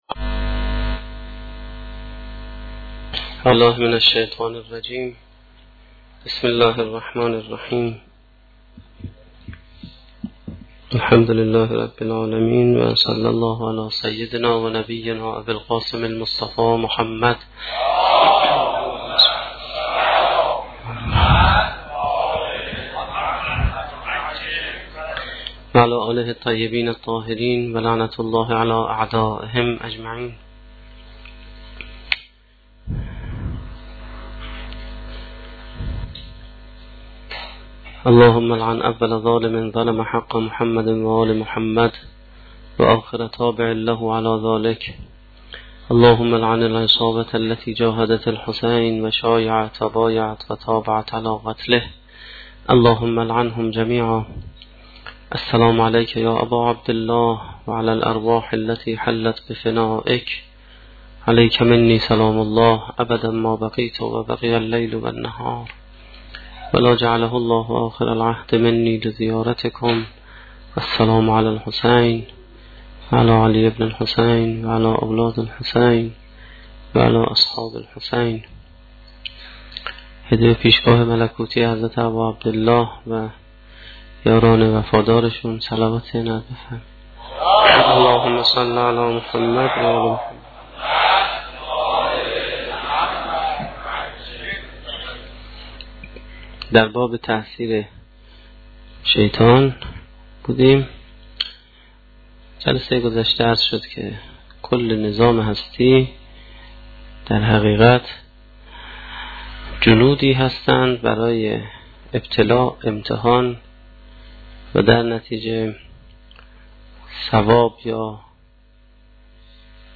سخنرانی هفتمین شب دهه محرم1435-1392